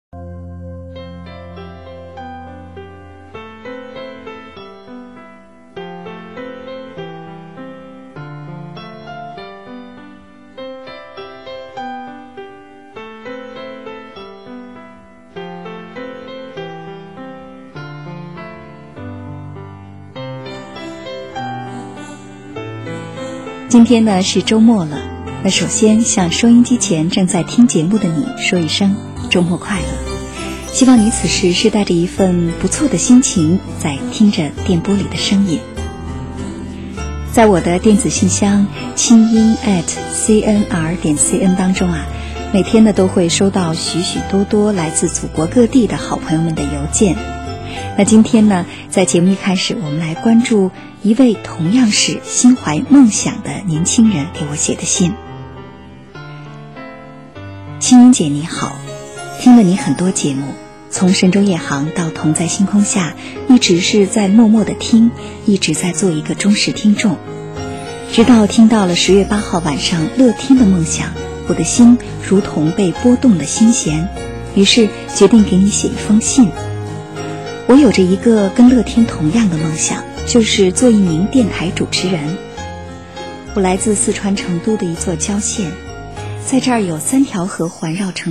[17/12/2008]真心跪求钢琴曲名(文件已上传)
这个音乐是当初在广播上听到的，刚听是以为是Kevin Kern的Sundial Dreams，但细一听发现很不对，希望大家多多帮助，曲子太短，没办法，截出来的